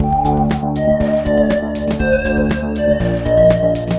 The Tech (Full) 120BPM.wav